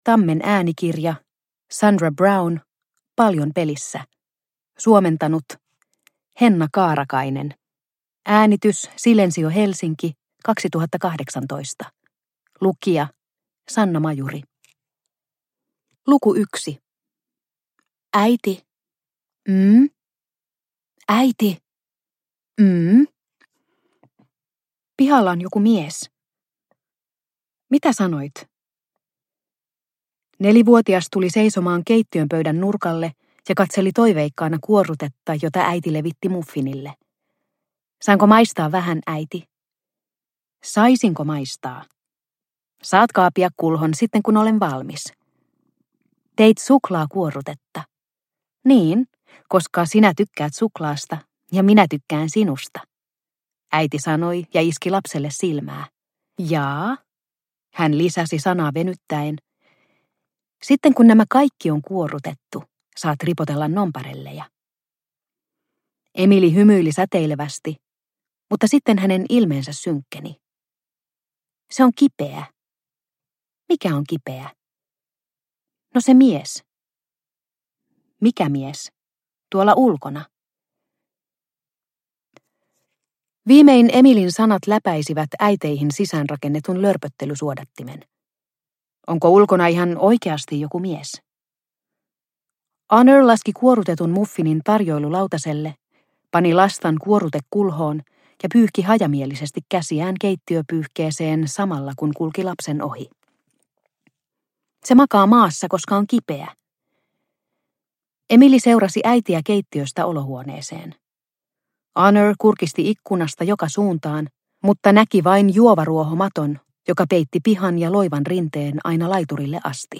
Paljon pelissä – Ljudbok – Laddas ner